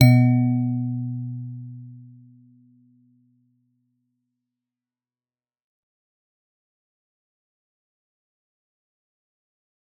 G_Musicbox-B2-f.wav